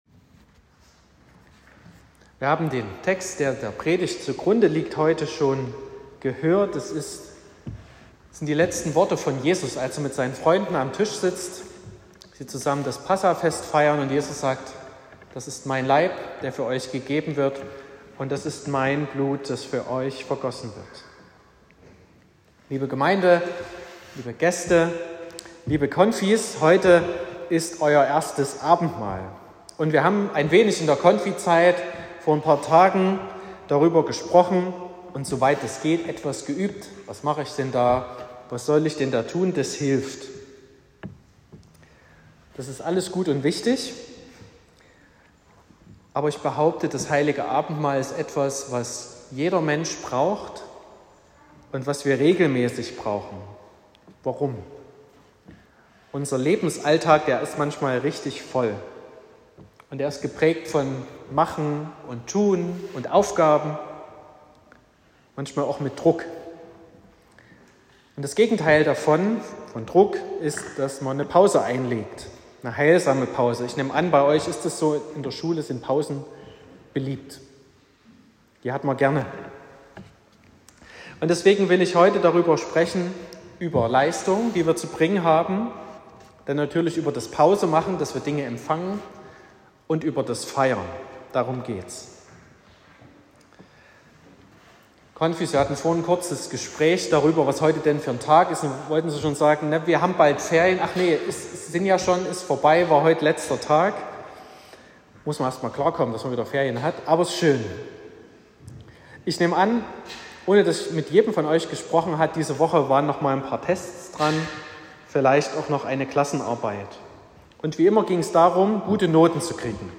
17.04.2025 – gemeinsamer Gottesdienst mit Erstabendmahl der Konfirmanden Kl. 7
Predigt (Audio): 2025-04-17_Das_Heilige_Abendmahl_veraendert_uns.m4a (7,4 MB)